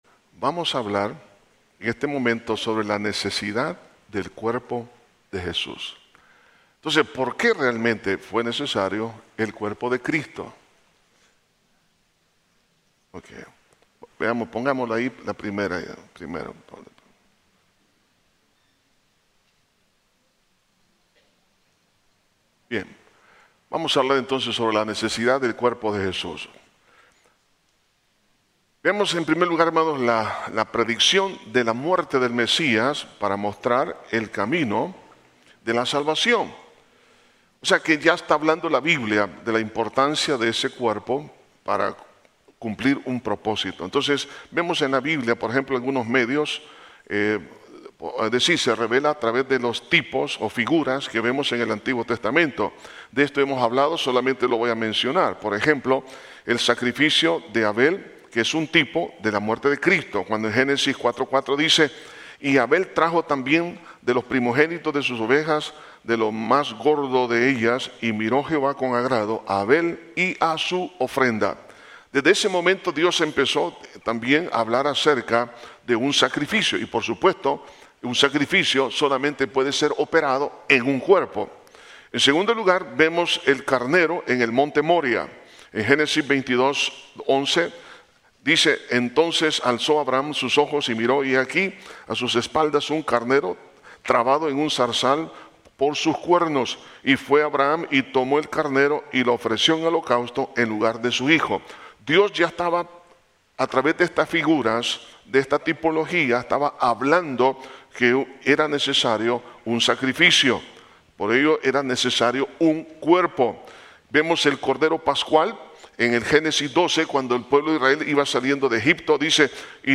Estudio de Cirstologia